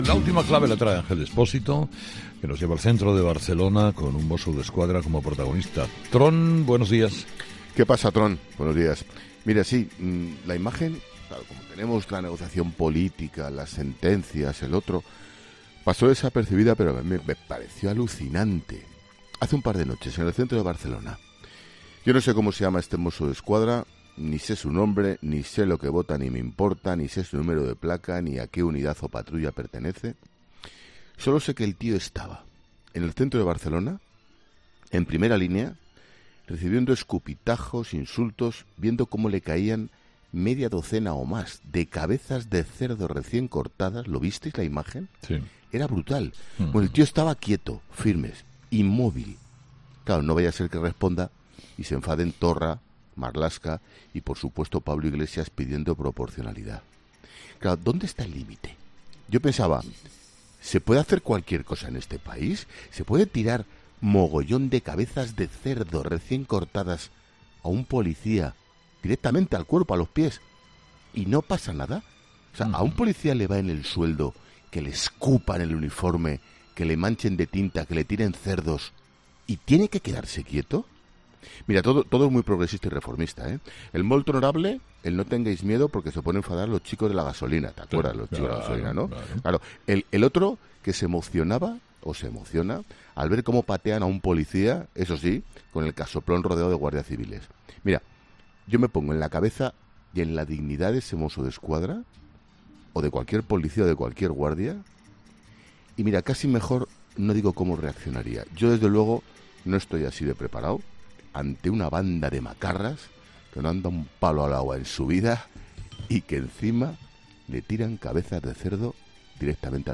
Ángel Expósito hace su paseíllo en 'Herrera en COPE'